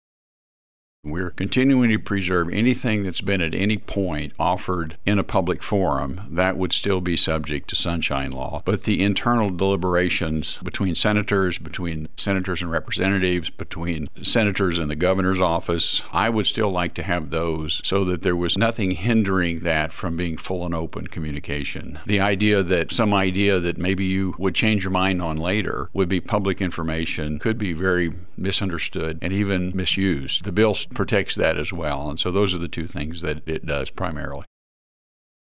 1. Senator Emery says Senate Bill 132 seeks to authorize closure of certain constituent and legislative records of members of the Missouri General Assembly.